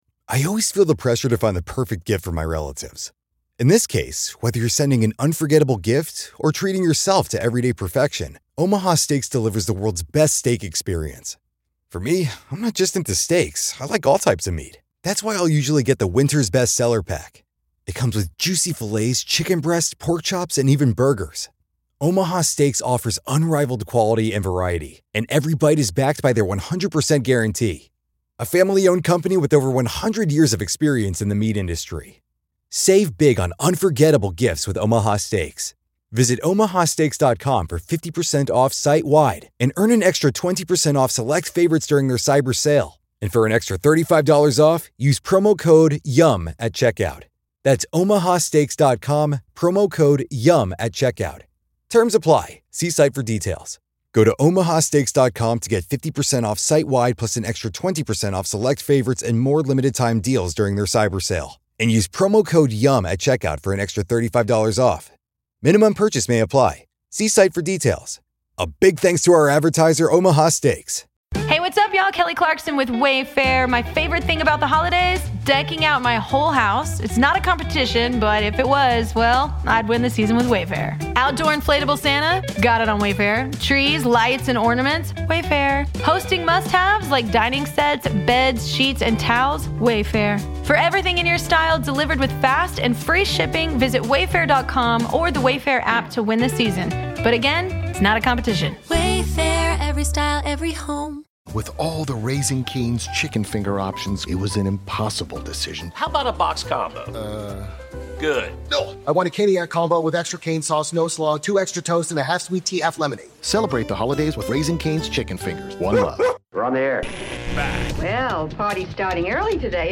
Grab a cup of coffee, turn up the volume, and imagine you're right alongside them in studio!!